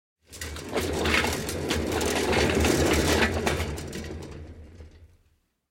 Звуки тележки, вагонетки
Звук Еще вариант (короткий) (00:06)